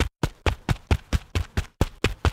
running-2.wav